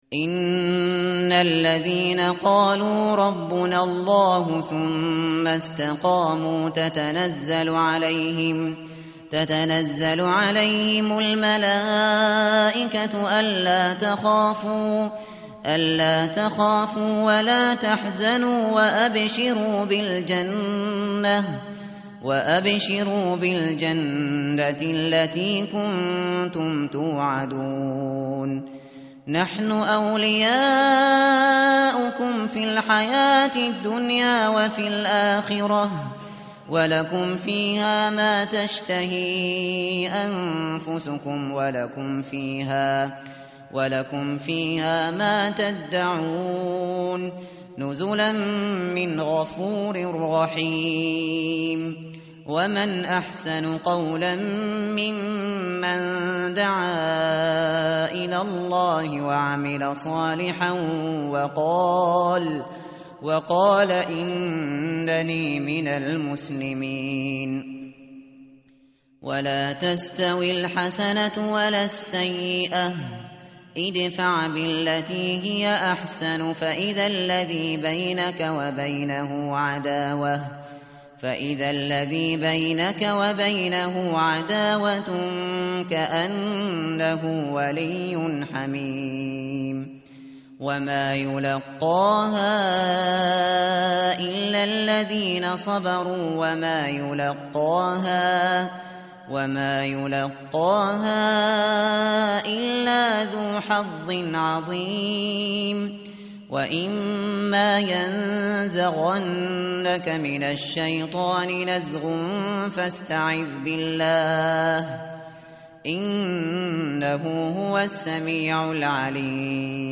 متن قرآن همراه باتلاوت قرآن و ترجمه
tartil_shateri_page_480.mp3